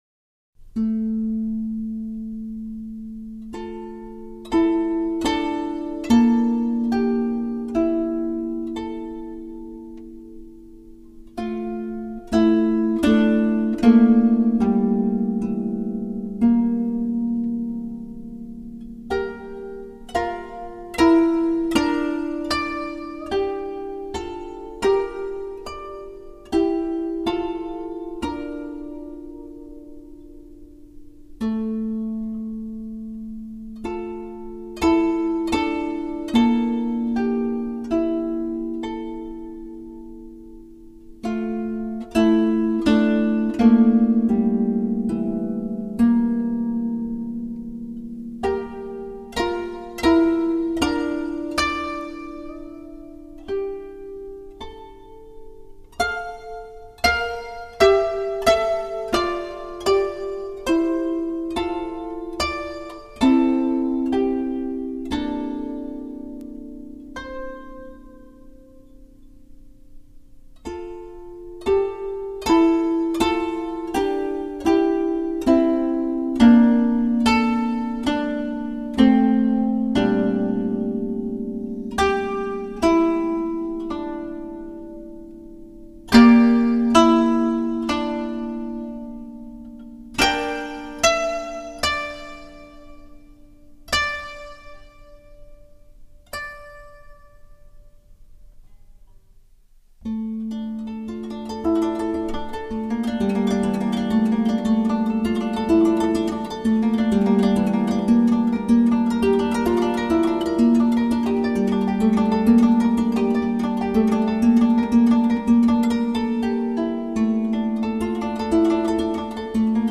25弦古筝（日本古筝）
据说日本乐器发出的音乐都是其与生俱来的自然的声音，那么25弦古筝里面的声音就是春风微拂的声音。
因此它的音域要宽得多，甚至可以让你联想到竖琴。
这25根琴弦所弹奏出来的音符在空气中飘荡，能让你忘记城市的噪音变化，从而享受完全的放松。